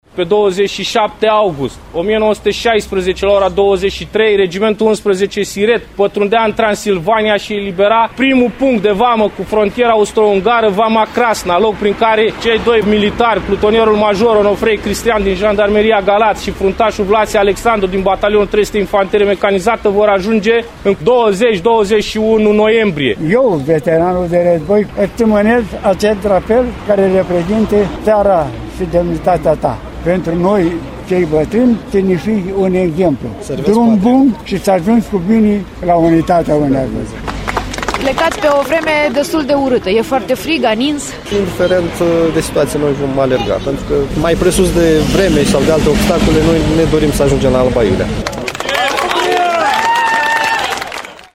La plecare, cei doi alergători au primit drapelul României de la doi veterani de război. O corespondență